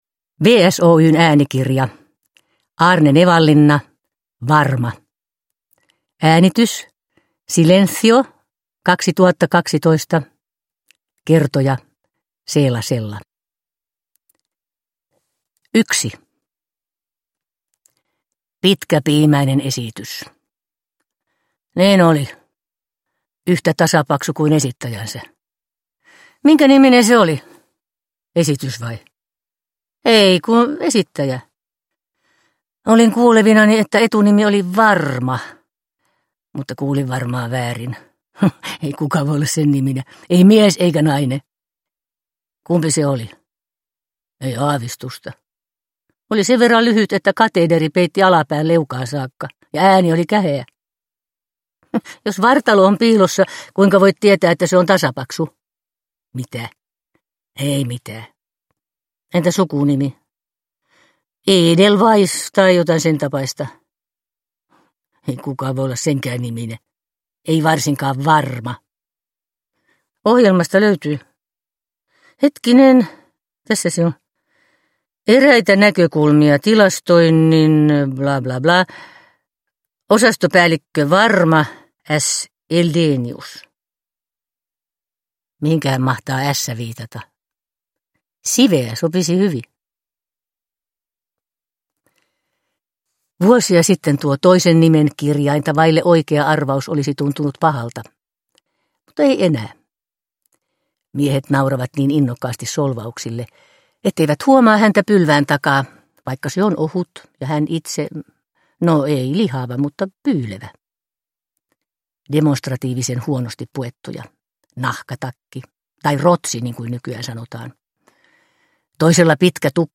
Äänikirjan kertoja on näyttelijä Seela Sella.
Uppläsare: Seela Sella